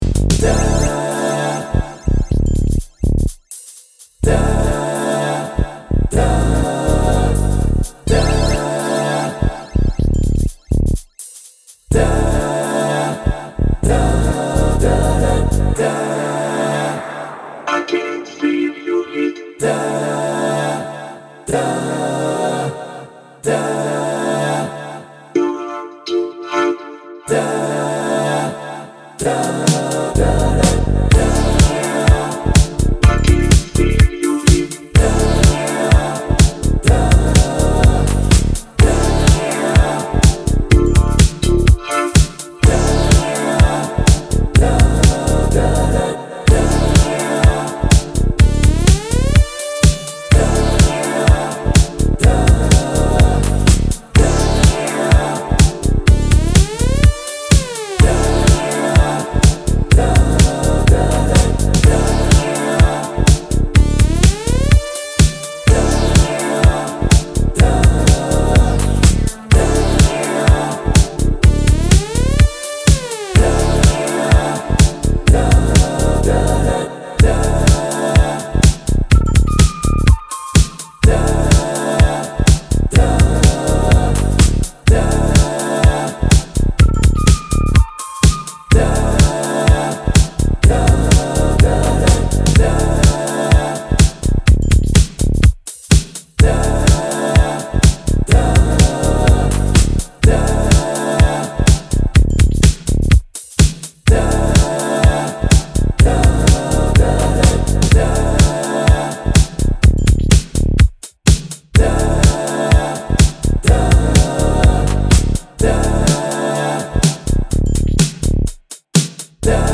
a banger